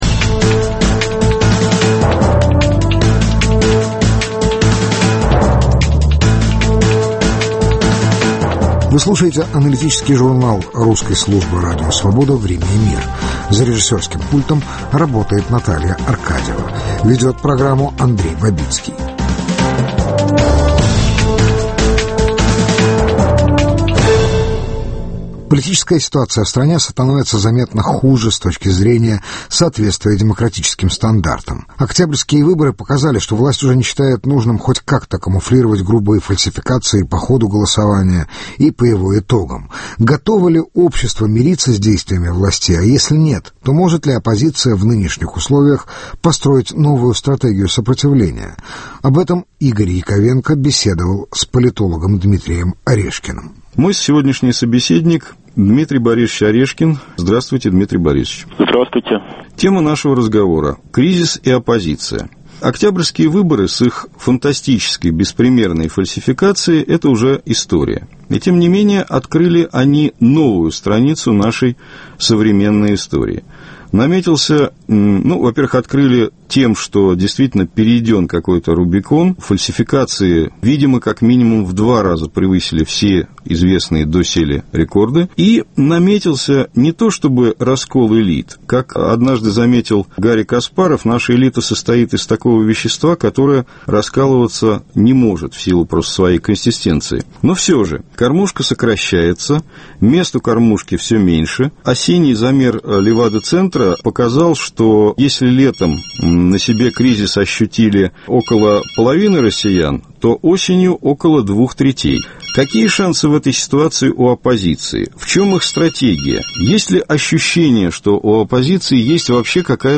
Беседа с политологом Дмитрием Орешкиным о перпективах оппозиционного движения в России